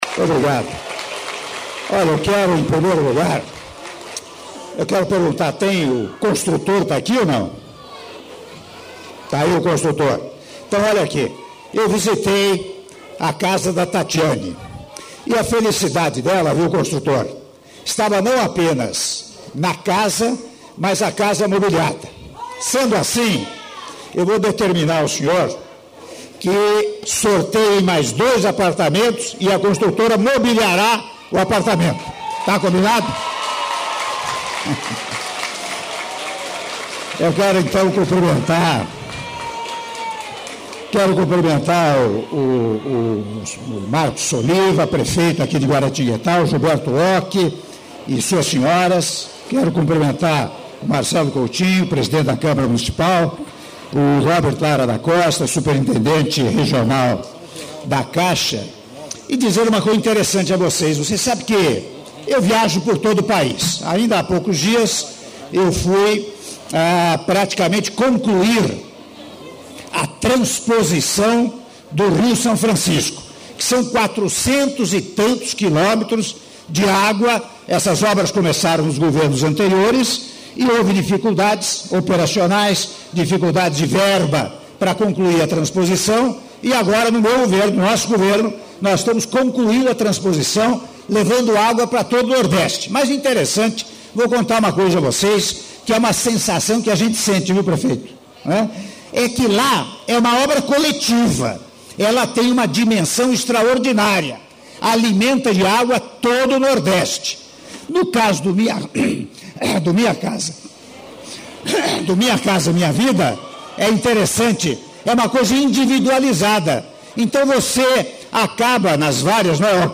Áudio do discurso do Presidente da República, Michel Temer, durante cerimônia de Entrega de 528 Unidades Habitacionais do Condomínio Residencial Flamboyant I, II e III - Guaratinguetá/SP- (04min34s)